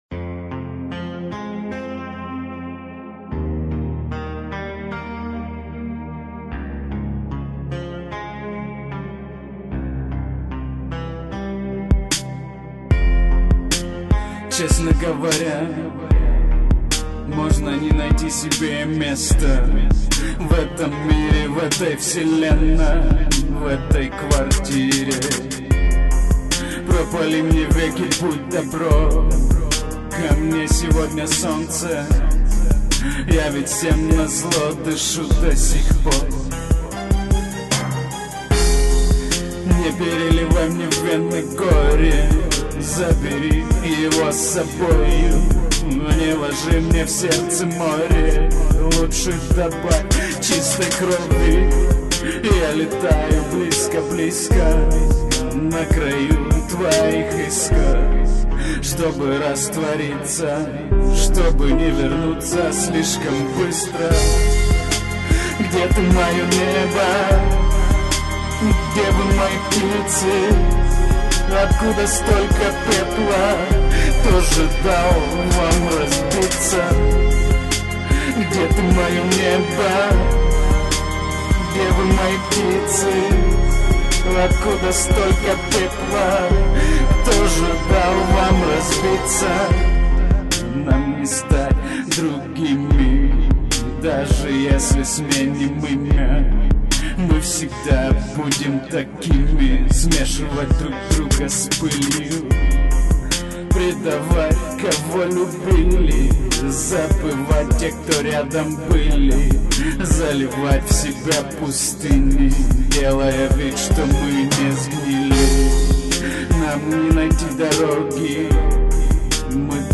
На рэп чем-то похоже
жалко запись плохого качества((( а за стихо +.